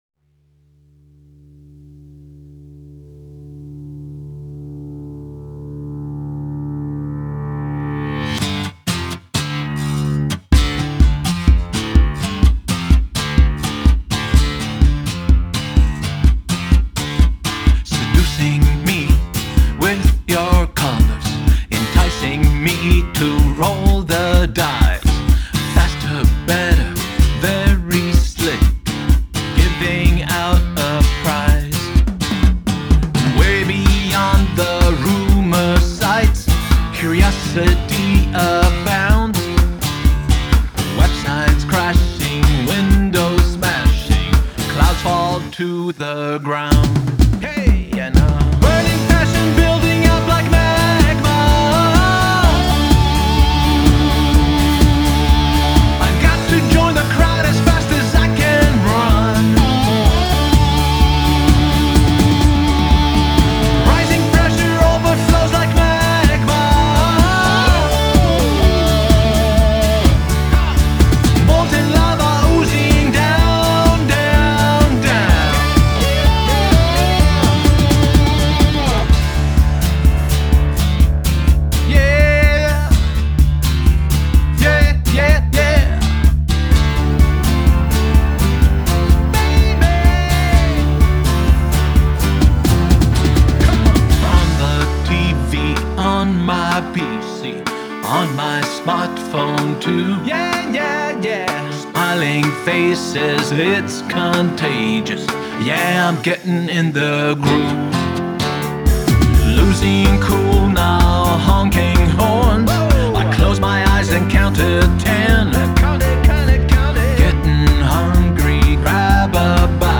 Genre: Pop Rock, Adult Alternative Pop/Rock